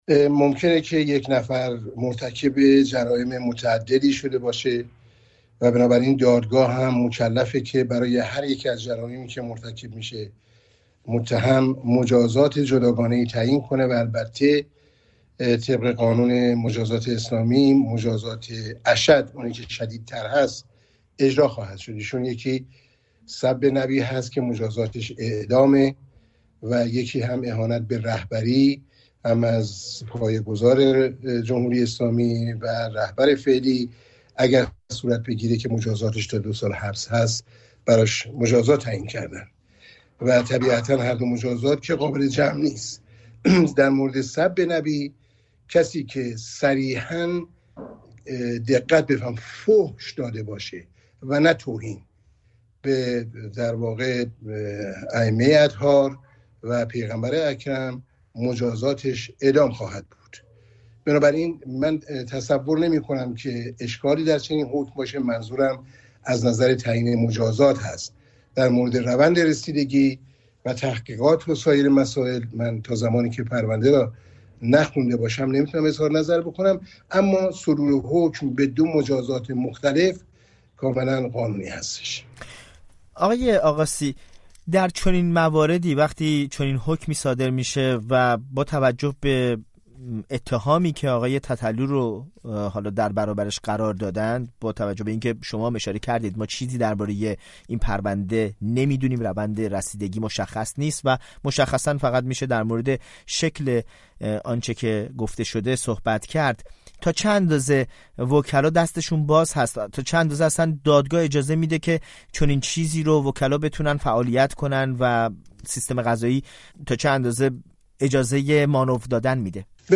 نگاه حقوقی به صدور حکم اعدام برای «تتلو» در گفت‌وگو